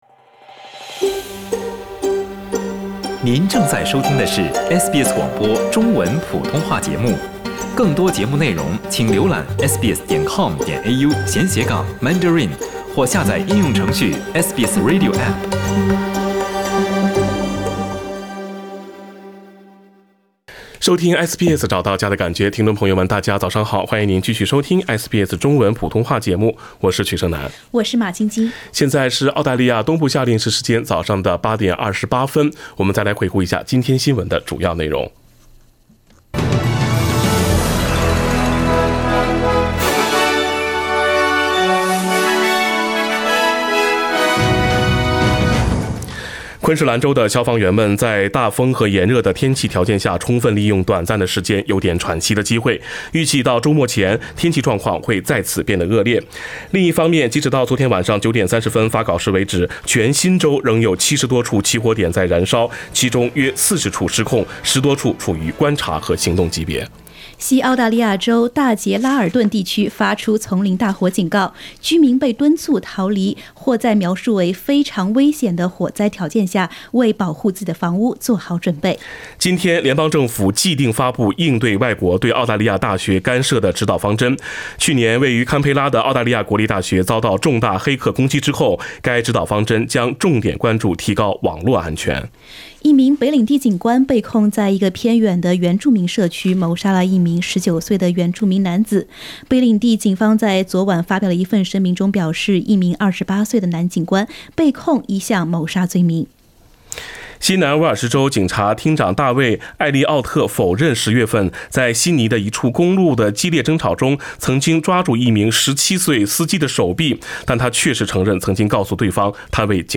SBS早新闻 （11月14日）